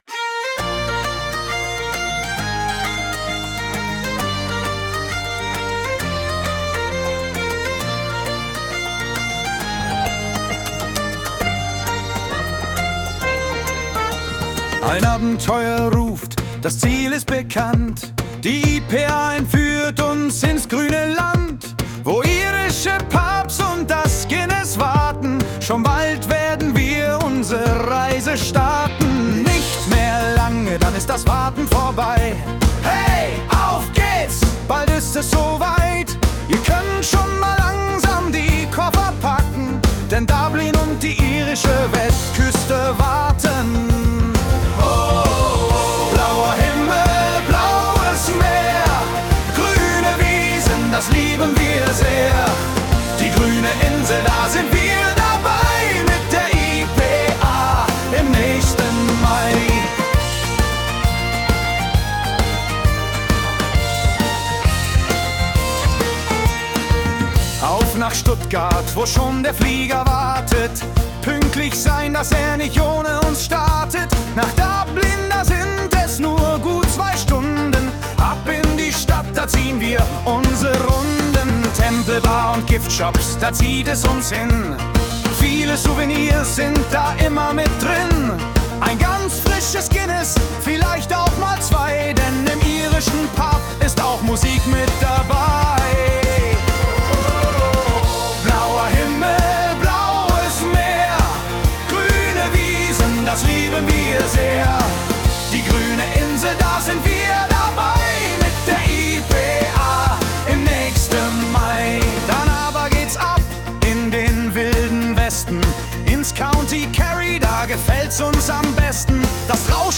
Was es schon gibt, ist ein eigener (KI-generierter) Song für unsere Tour!